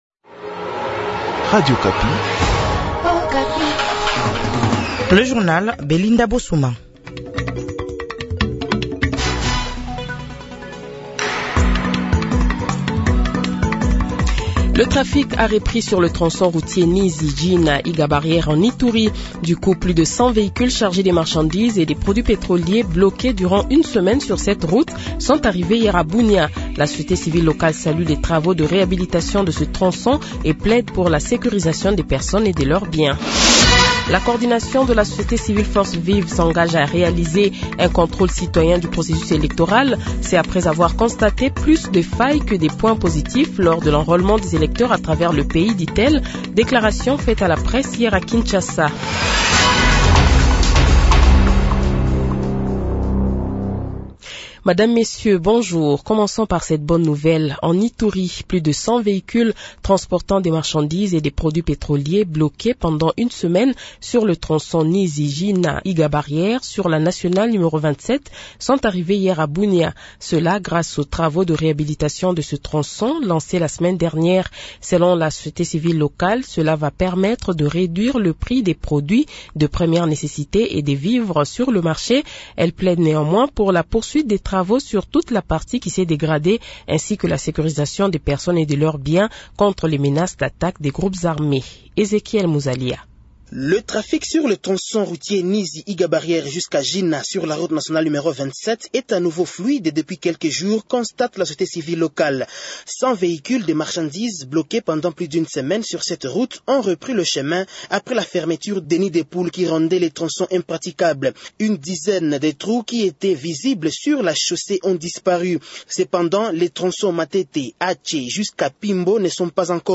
Journal Matin
Le Journal de 7h, 20 Avril 2023 :